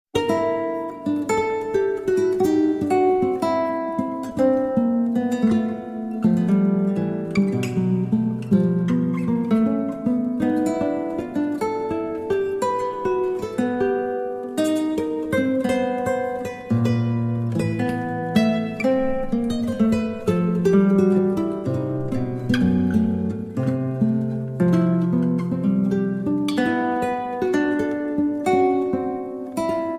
Shimmering Guitar and Lute Duets Composed and Improvised